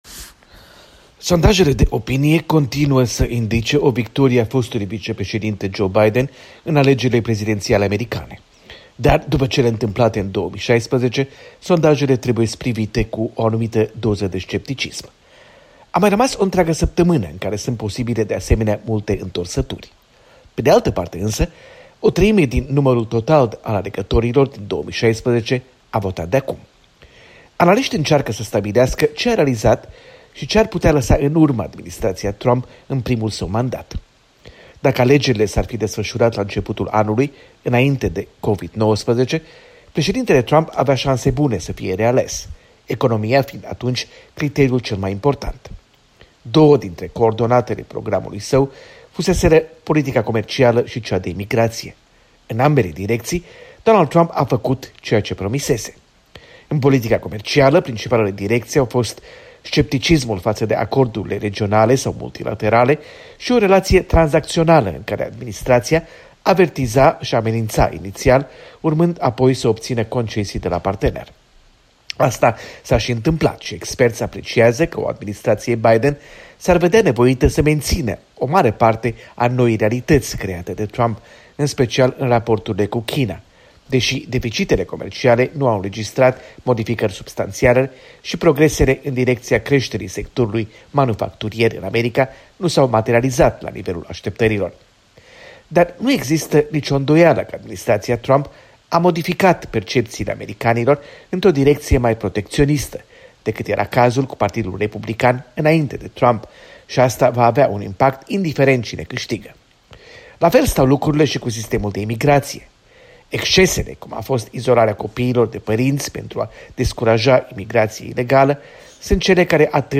Corespondență de la Washington.